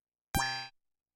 Yamaha CS30 L Changes Length " Yamaha CS30 L Changes Length F4 ( Changes Length67127)
标签： MIDI-速度-96 FSharp4 MIDI音符-67 Yamaha- CS-30L 合成器 单票据 多重采样
声道立体声